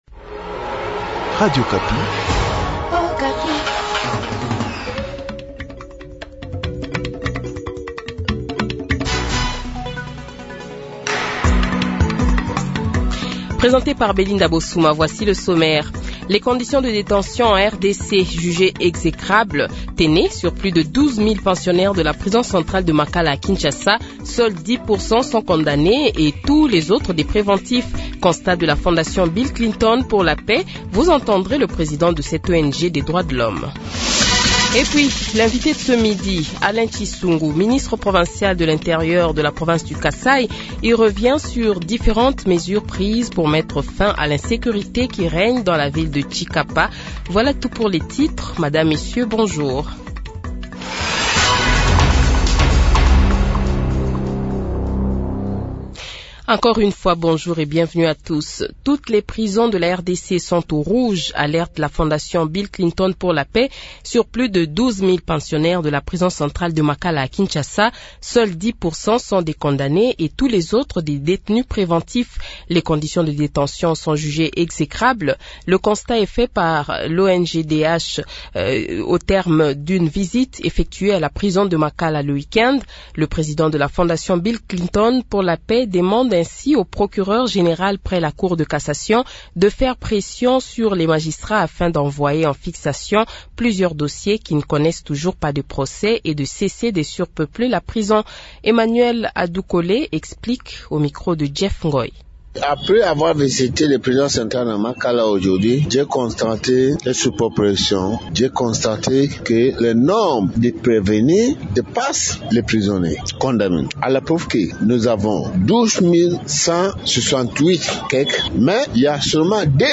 Journal Francais Midi
INVITE : Alain Tshisungu, ministre de l’Intérieur du Kasaï sur l’insécurité à Tshikapa